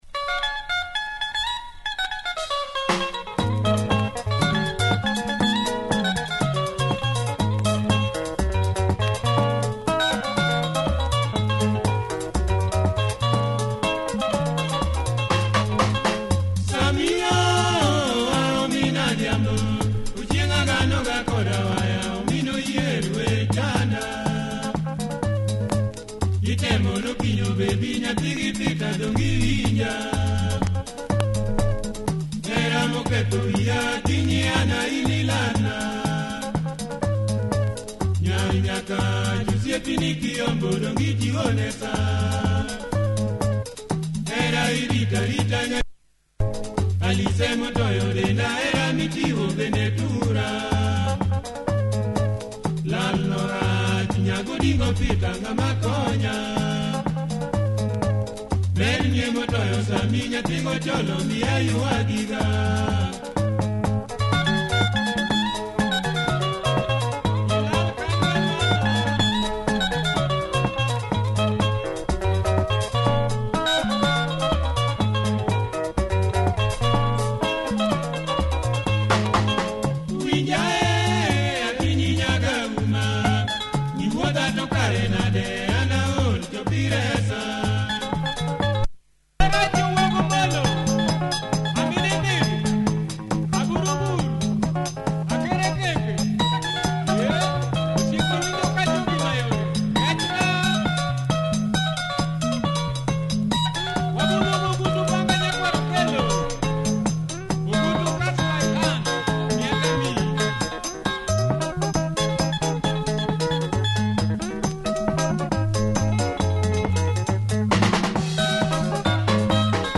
slowburning Luo benga with nice vibe